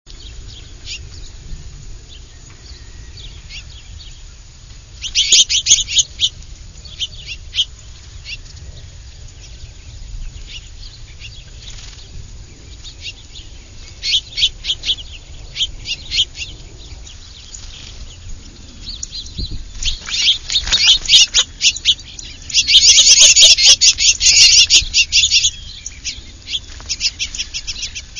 Eastern Bluebird
Bluebirds457.wav